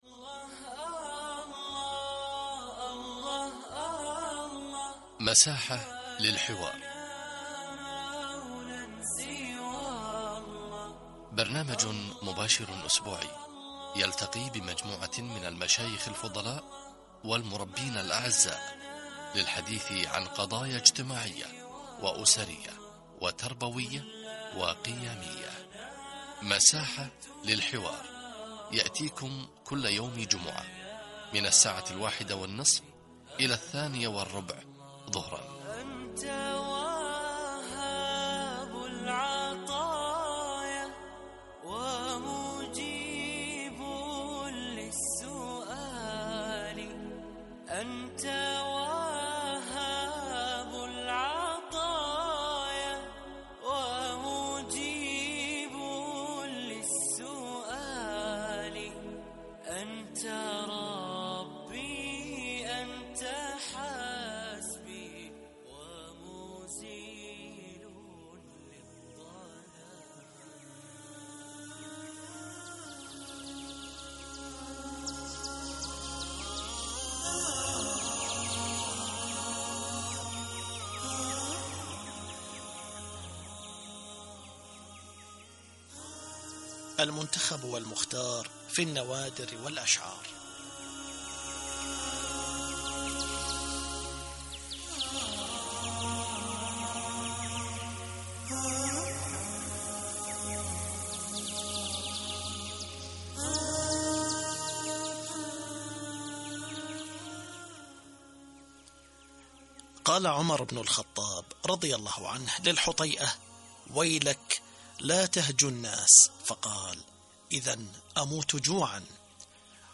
لقاء إذاعي - وقفات مع الإجازة الصيفية